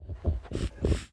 Minecraft Version Minecraft Version snapshot Latest Release | Latest Snapshot snapshot / assets / minecraft / sounds / mob / sniffer / searching5.ogg Compare With Compare With Latest Release | Latest Snapshot